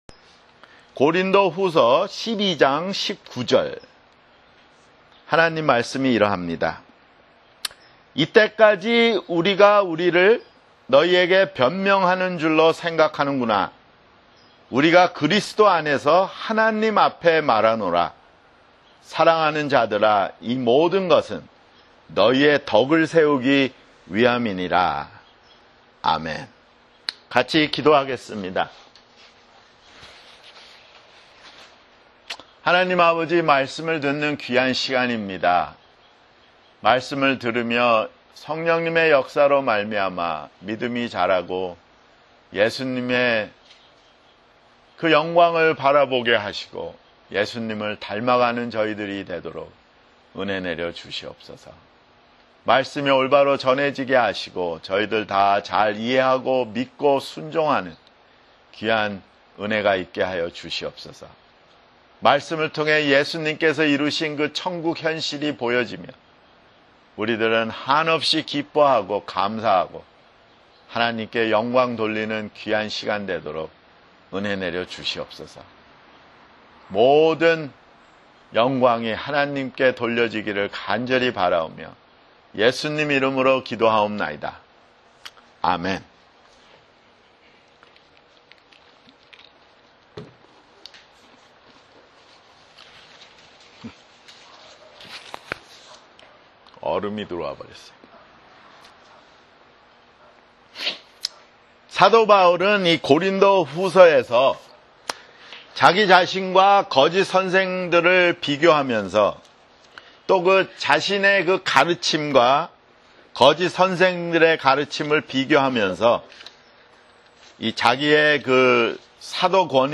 [주일설교] 고린도후서